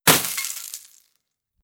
glass-breaking.wav